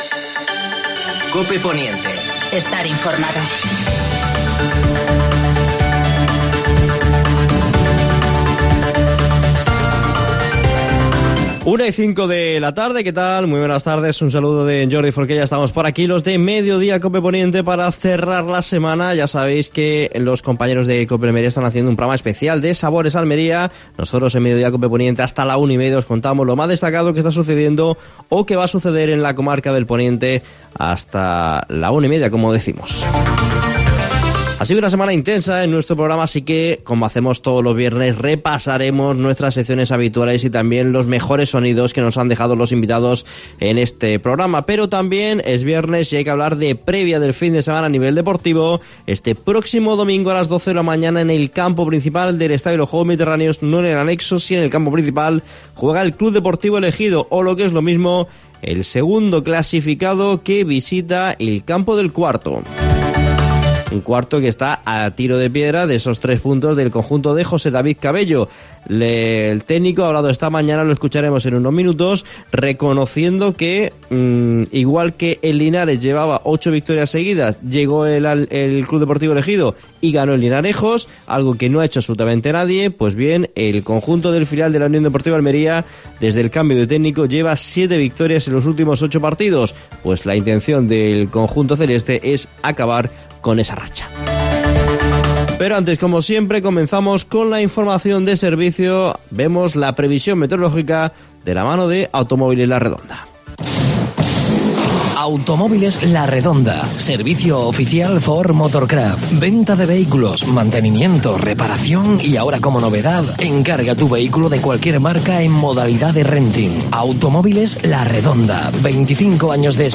Como hacemos cada viernes, hemos repasado los mejores momentos vividos en nuestro programa a lo largo de la semana.